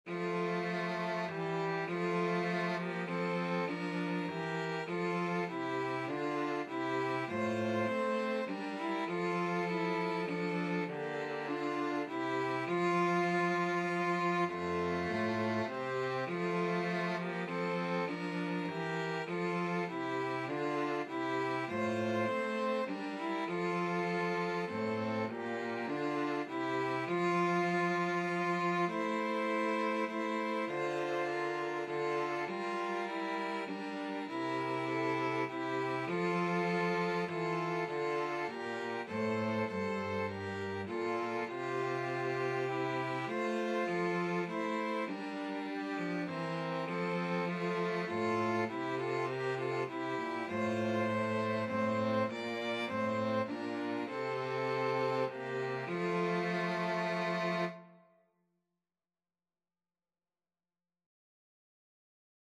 Violin 1Violin 2Cello
3/4 (View more 3/4 Music)
Classical (View more Classical 2-Violins-Cello Music)